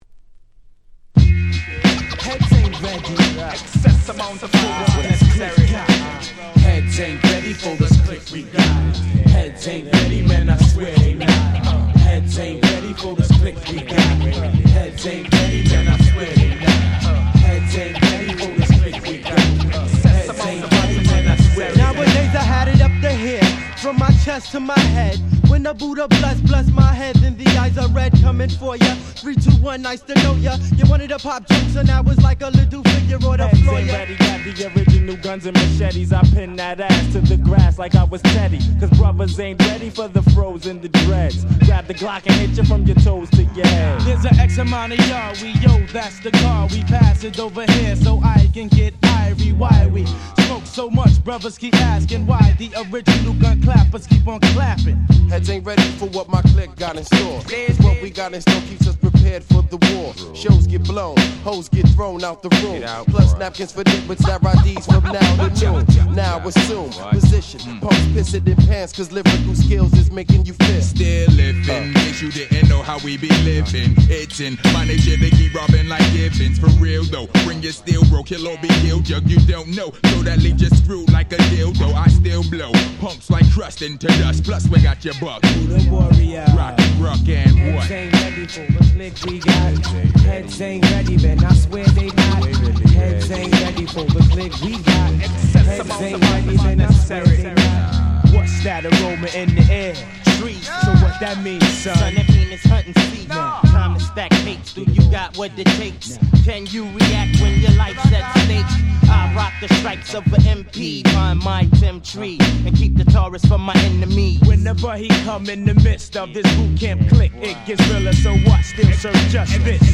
95' Smash Hit Hip Hop / Underground Hip Hop !!